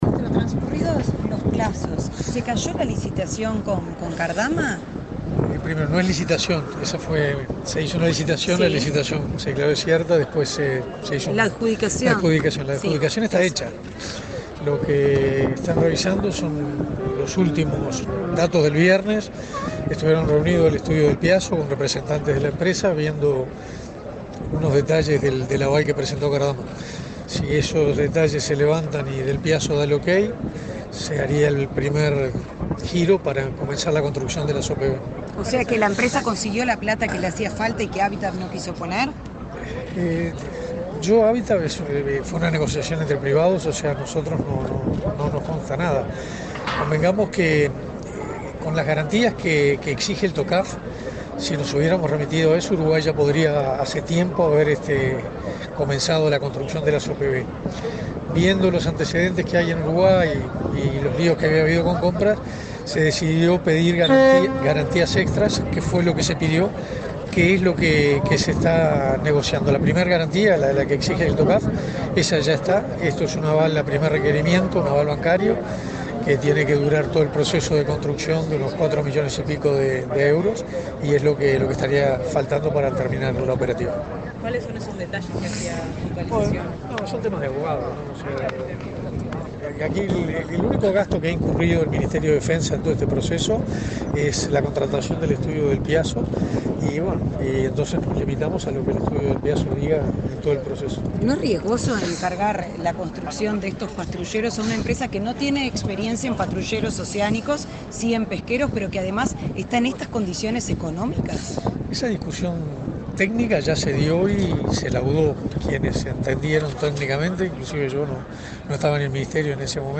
Declaraciones del ministro de Defensa Nacional, Armando Castaingdebat
El ministro de Defensa Nacional, Armando Castaingdebat, dialogó con la prensa en el puerto de Montevideo, luego del arribo del velero escuela ROU 20,